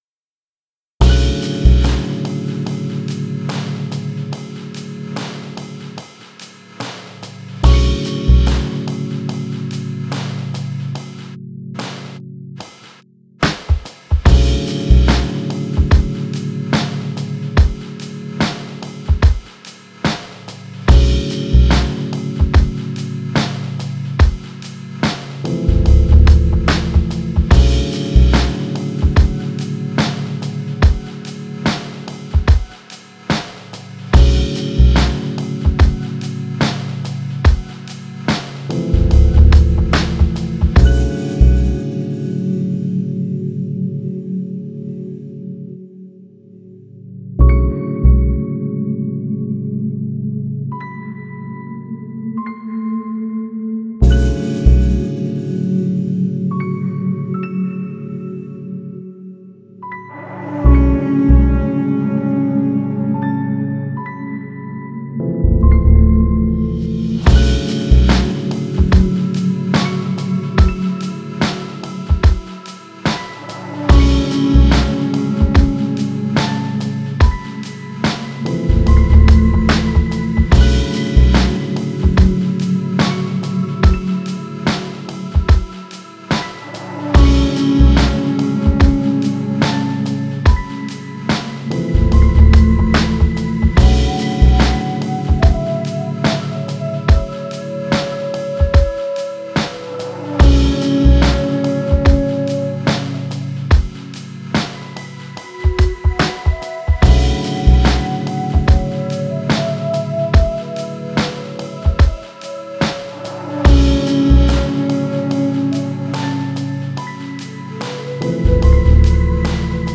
Lazy drum beat with rhodes and deep lonely piano atmosphere.